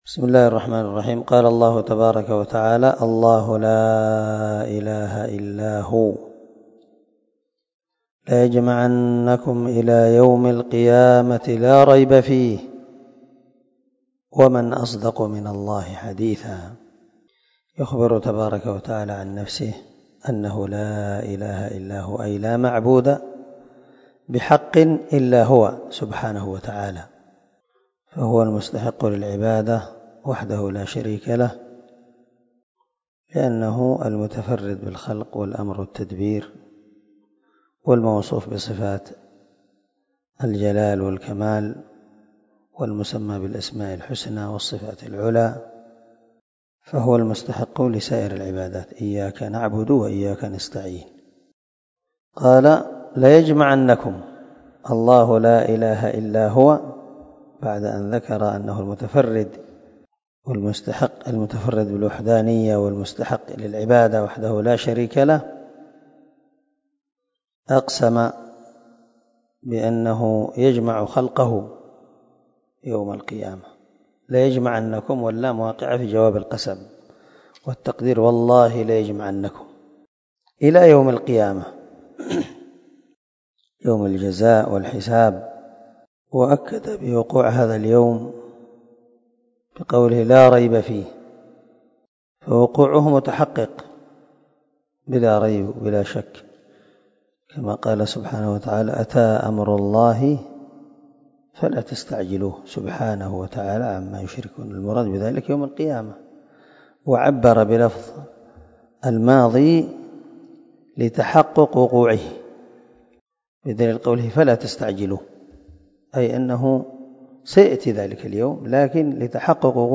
290الدرس 58 تفسير آية ( 87 ) من سورة النساء من تفسير القران الكريم مع قراءة لتفسير السعدي